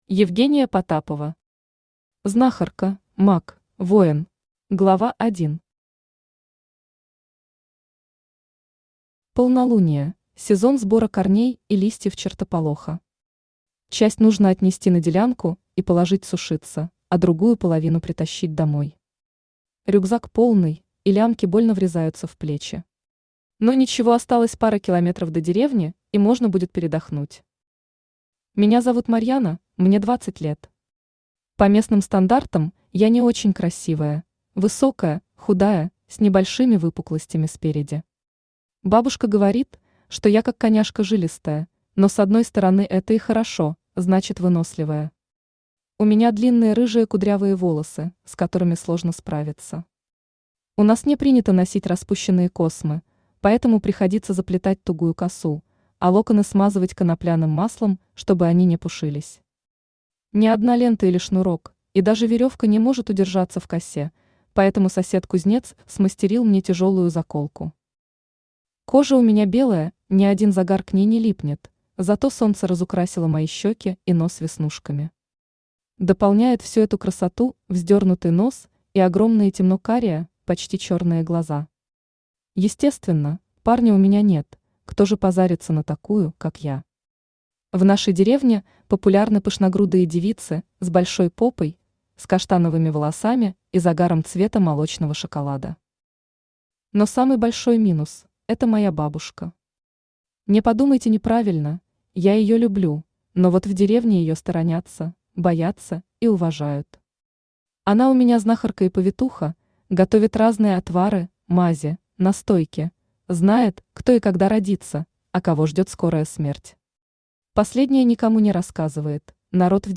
Aудиокнига Знахарка, маг, воин Автор Евгения Потапова Читает аудиокнигу Авточтец ЛитРес.